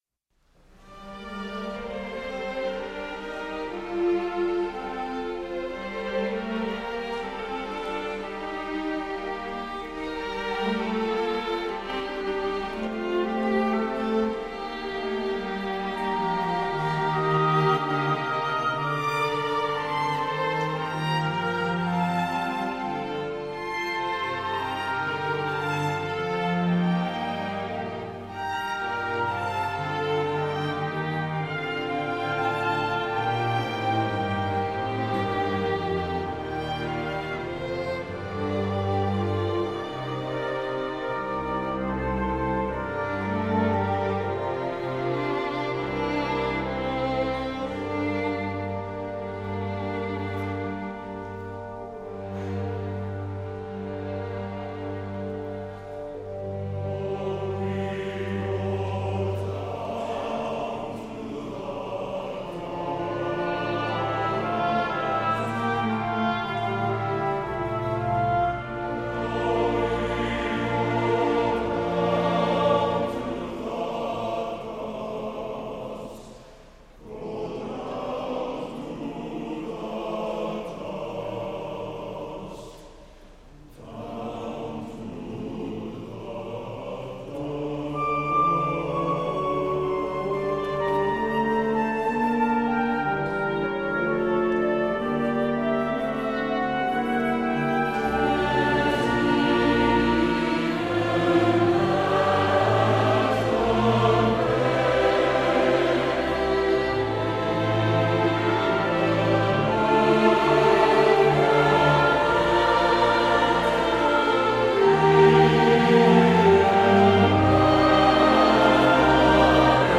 Music from the first performance